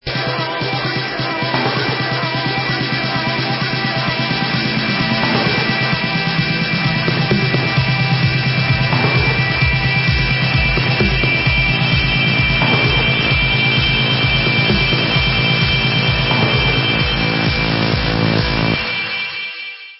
sledovat novinky v oddělení Dance/Trance